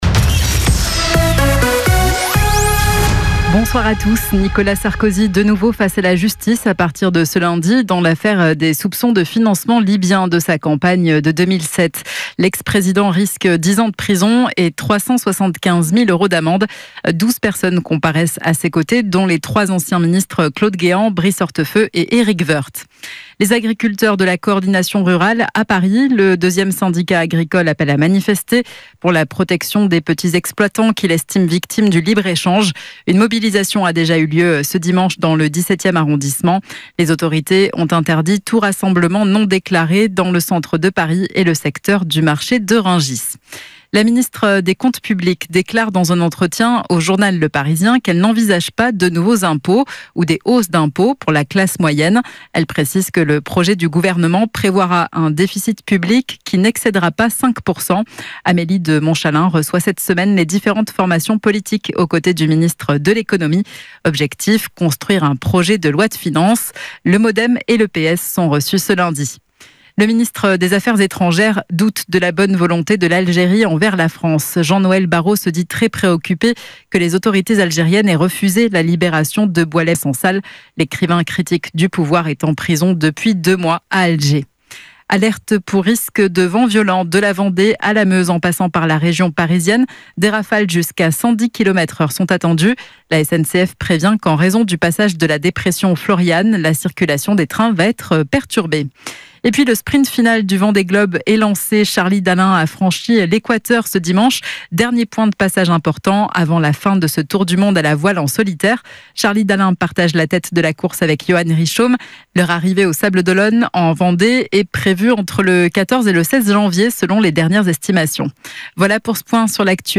em entrevista na Rádio Alfa